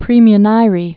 (prēmy-nīrē)